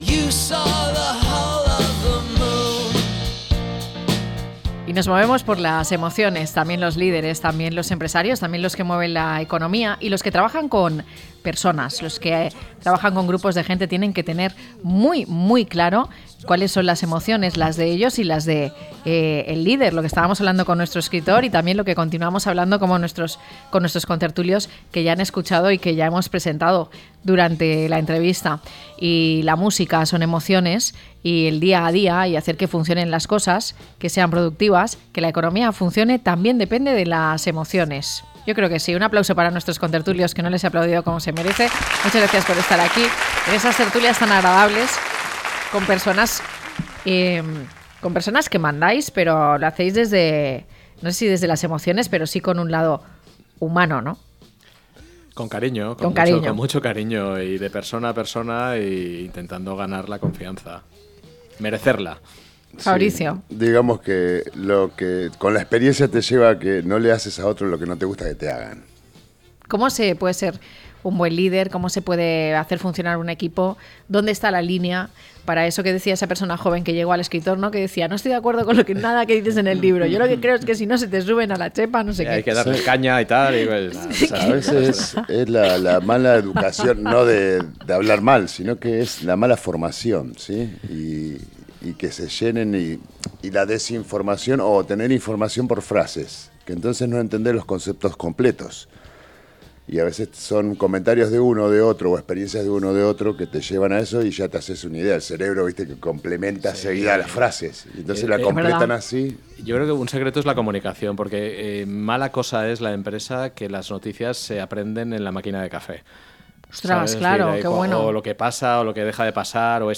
Tertulia empresarial